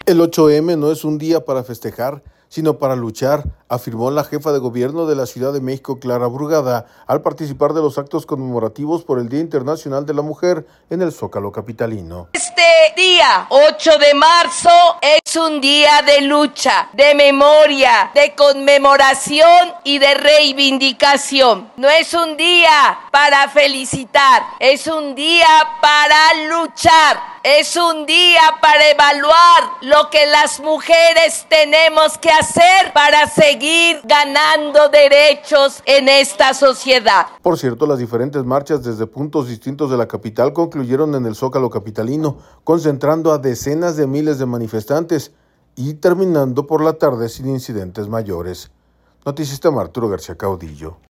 El 8 M no es un día para festejar, sino para luchar, afirmó la Jefa de Gobierno de la Ciudad de México, Clara Brugada, al participar de los actos conmemorativos por el Día Internacional de la Mujer en el zócalo capitalino.